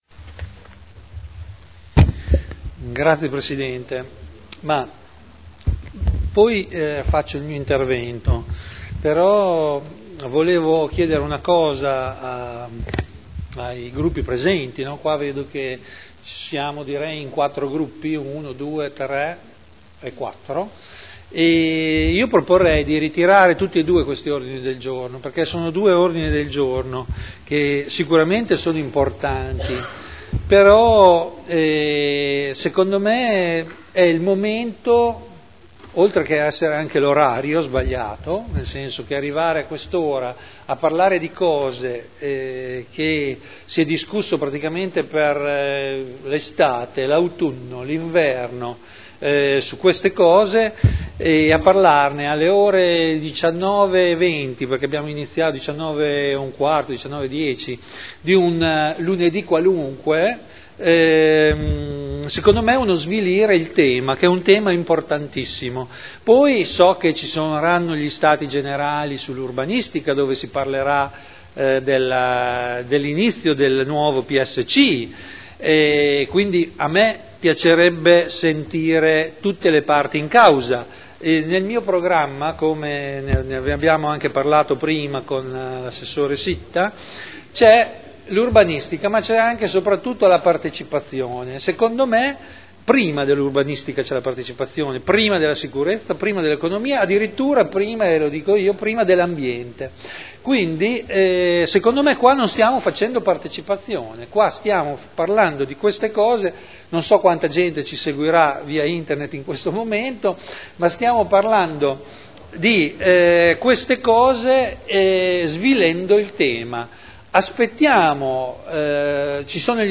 Seduta del 09/01/2012. Dibattito sui due ordini del giorno su Social Housing e riqualificazioni urbana.